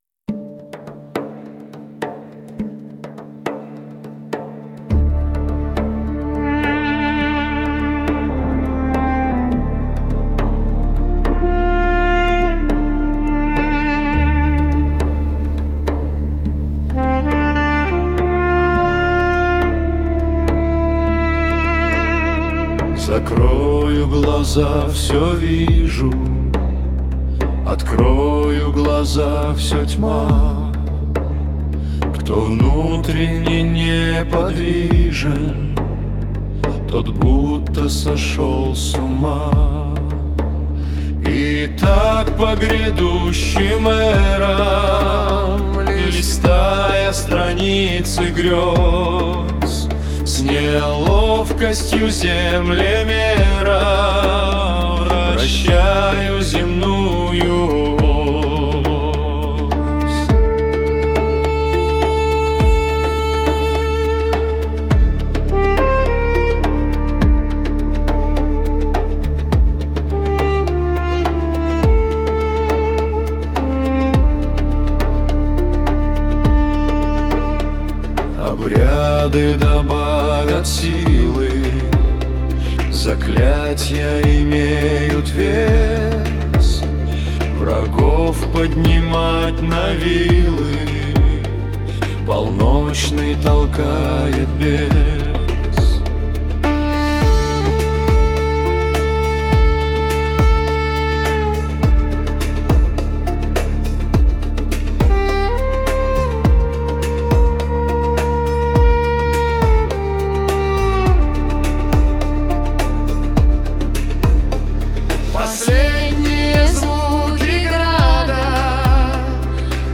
Один - мультиинструменталист, другой - бас и ударные.
Фолк